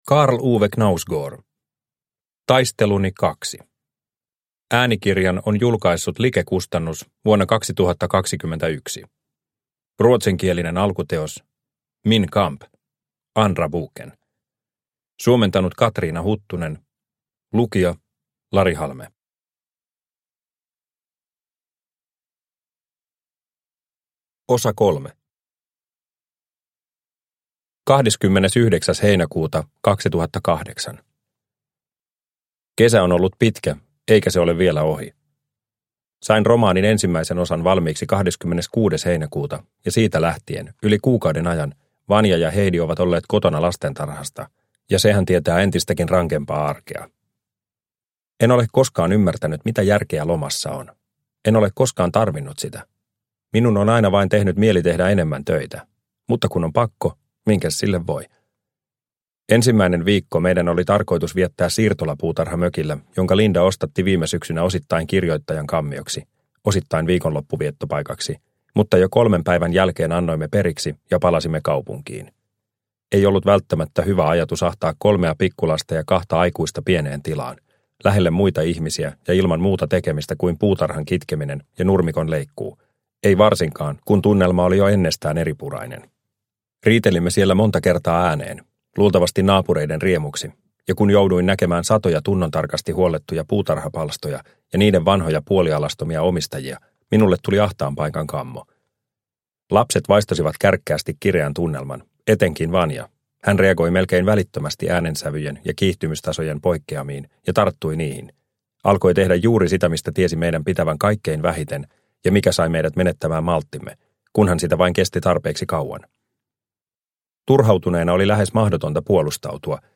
Taisteluni II – Ljudbok – Laddas ner